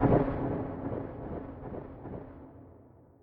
StepPD1.ogg